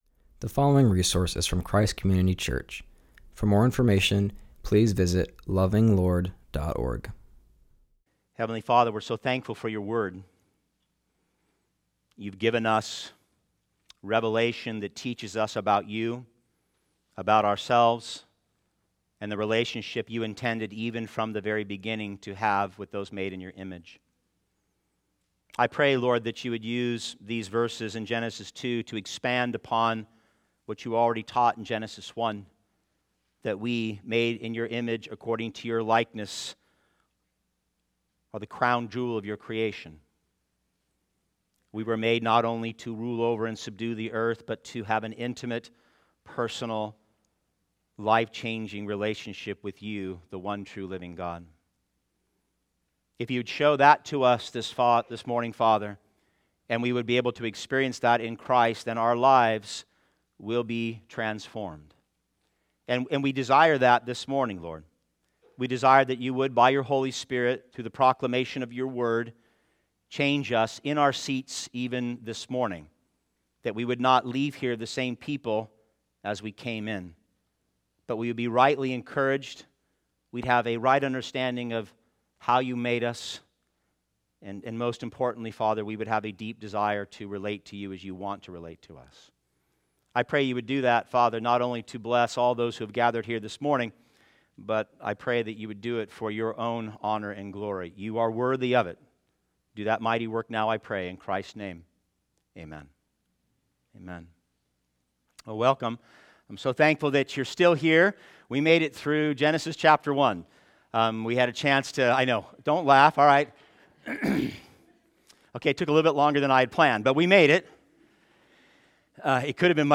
continues our series and preaches from Genesis 2:4-7.